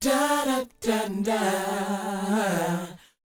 DOWOP D 4D.wav